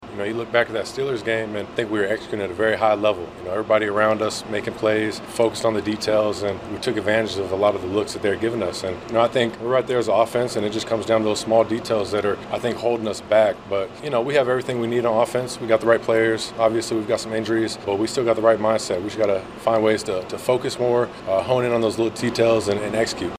Sputtering offense: Packers quarterback Jordan Love talked about how it wasn’t too long ago that the Packers had no problems scoring points.